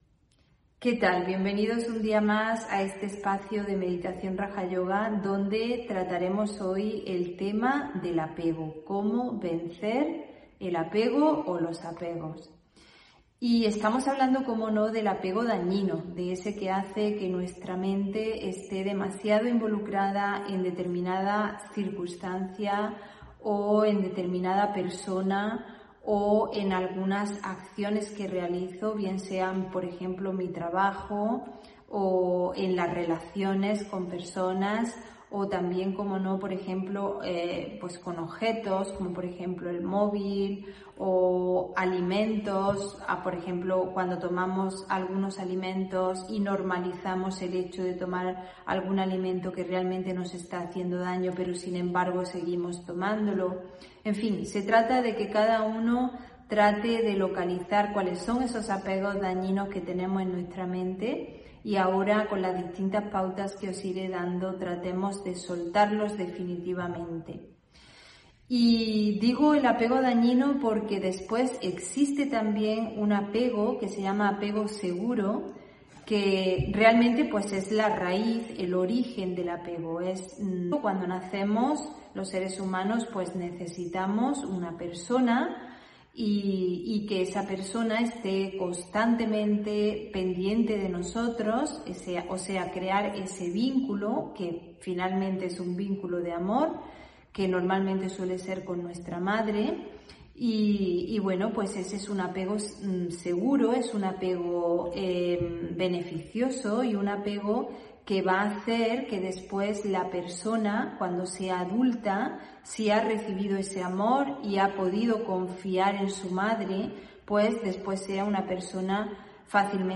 Meditación Raja Yoga y charla: Vencer el apego (13 Julio 2021) On-line desde Canarias